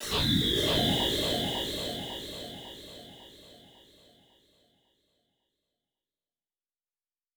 SouthSide Trap Transition (25).wav